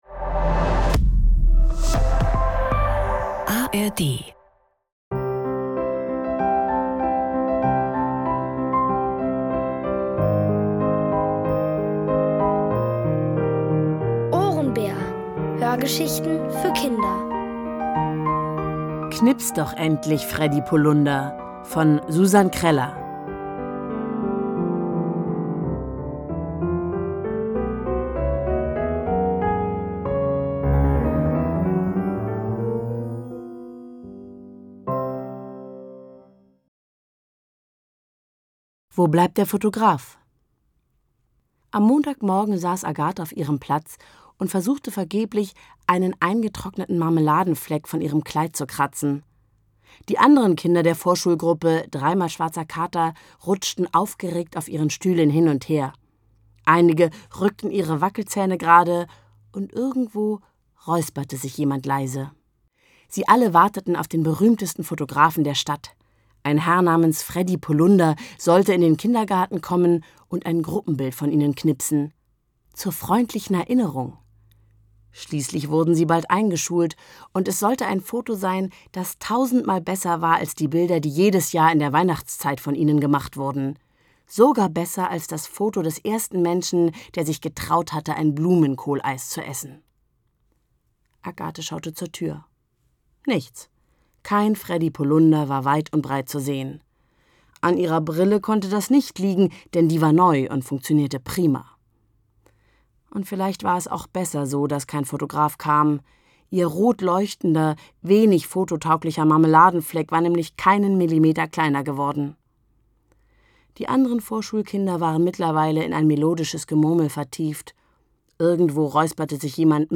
Alle 5 Folgen der OHRENBÄR-Hörgeschichte: Knips doch endlich, Freddy Pullunder von Susan Kreller.